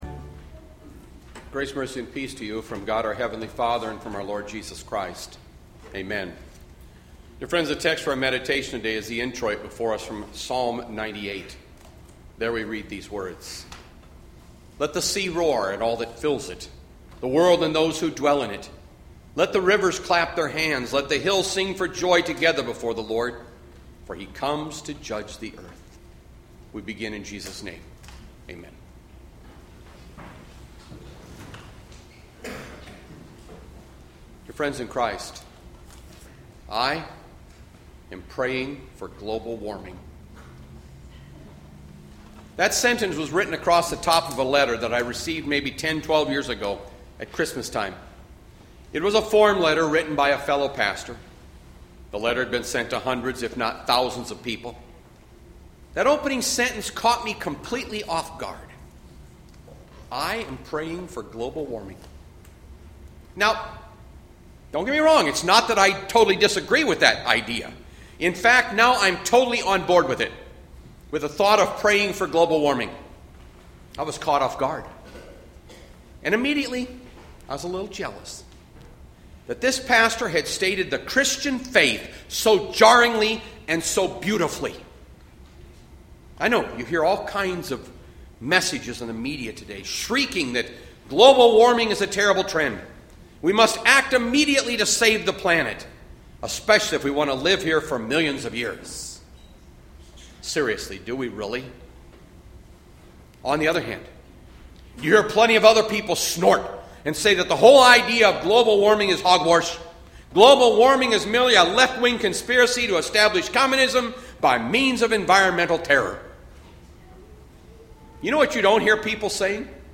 Bethlehem Lutheran Church, Mason City, Iowa - Sermon Archive Feb 16, 2020